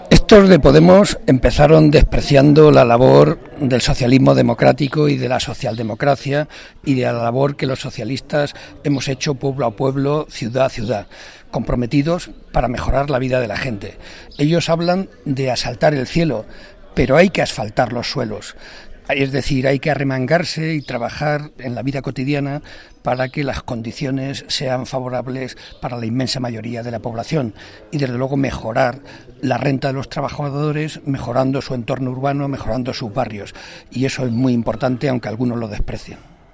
Encuentro con vecinos en Valverde
Cortes de audio de la rueda de prensa